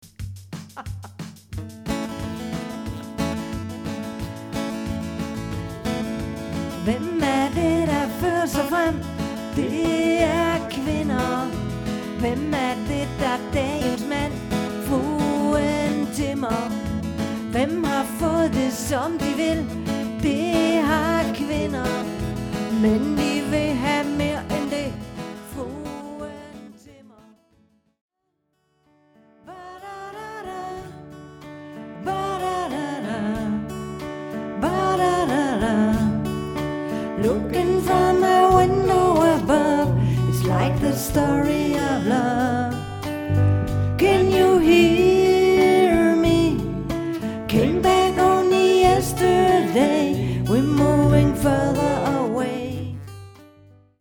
• Lydklip covers_ (Lydklip covers _)